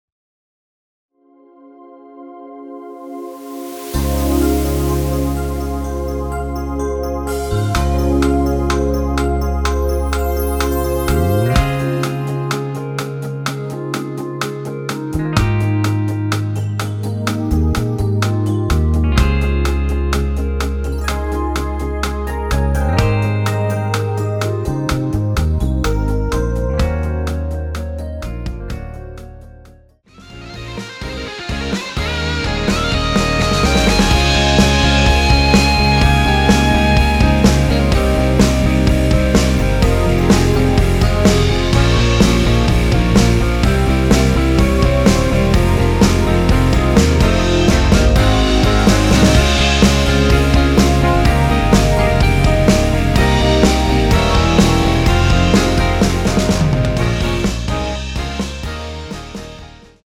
원키에서(-1)내린 멜로디 포함된 MR입니다.(미리듣기 확인)
Ab
앞부분30초, 뒷부분30초씩 편집해서 올려 드리고 있습니다.
중간에 음이 끈어지고 다시 나오는 이유는